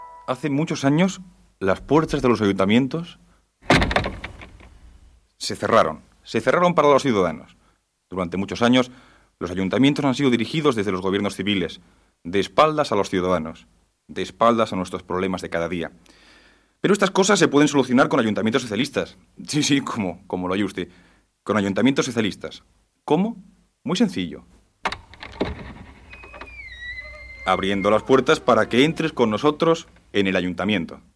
Propaganda electoral del Partido Socialista Obrero Español (PSOE) a les eleccions municipals del 3 d'abril de 1979